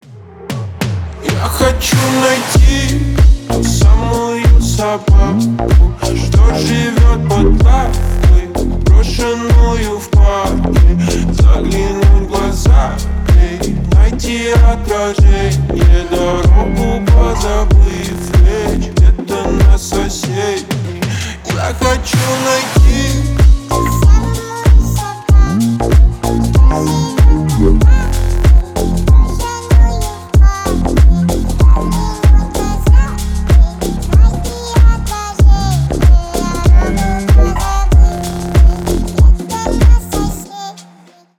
Ремикс # Рэп и Хип Хоп
грустные